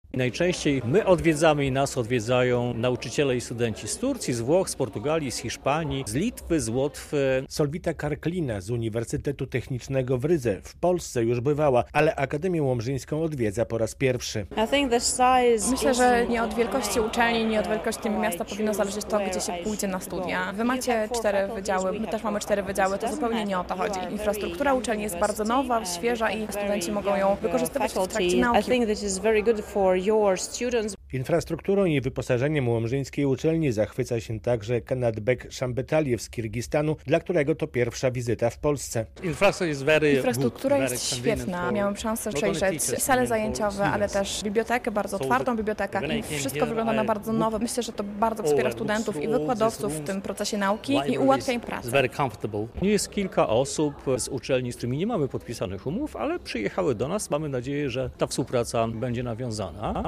Akademia Łomżyńska po raz kolejny zorganizowała International Staff Week - relacja